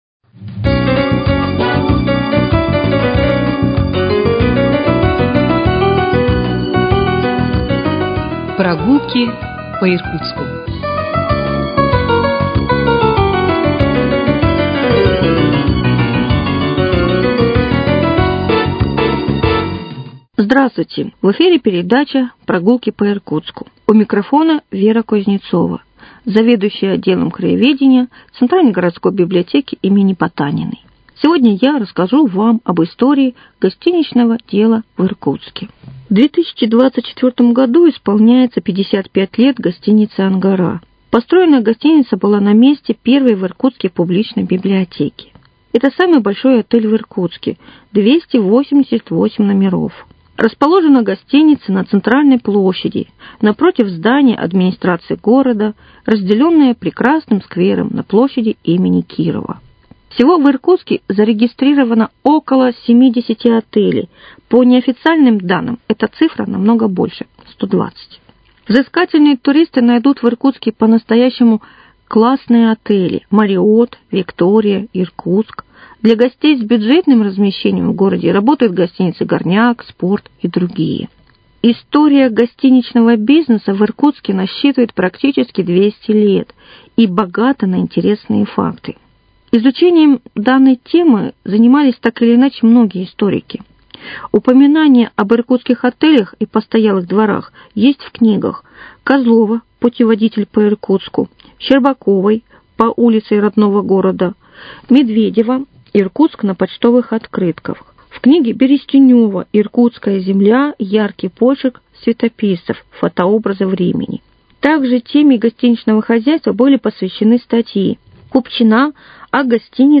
Мы продолжаем цикл передач - совместный проект радиоканала и Центральной городской библиотеки им. Потаниной.